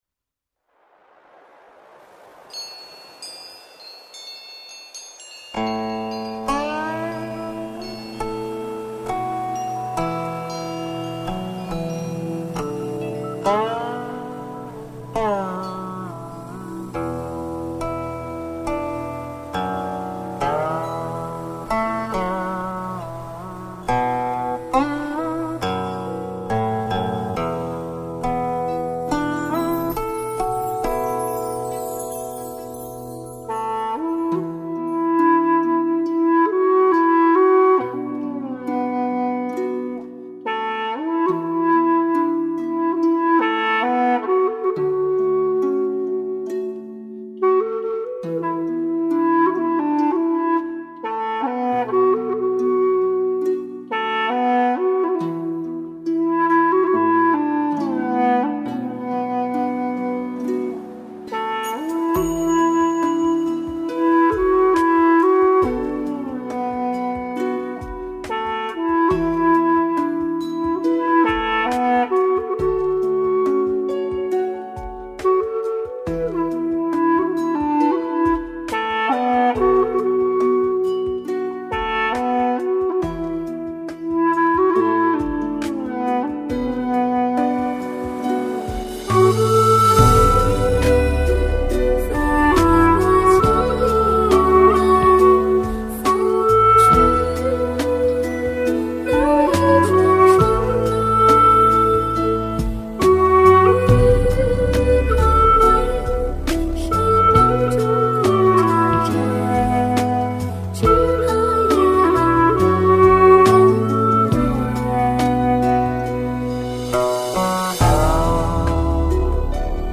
调式 : D 曲类 : 古风